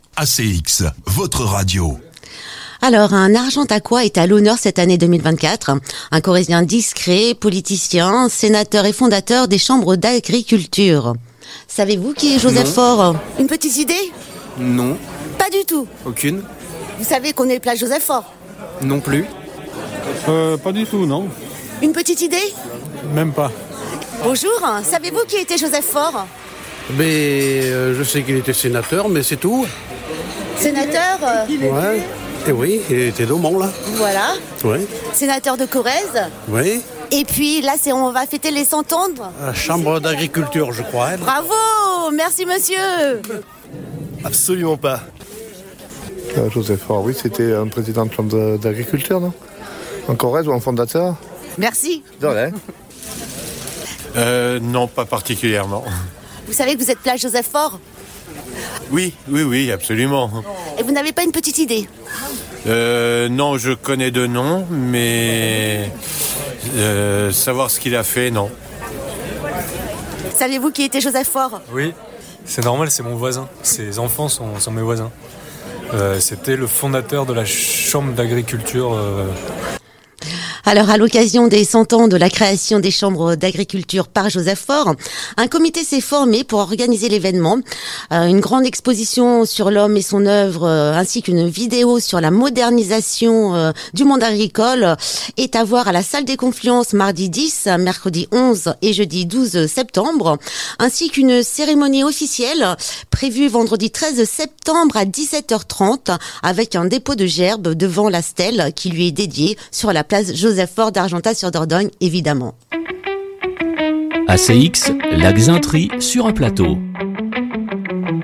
micro trottoir Joseph Faure - Radio ACX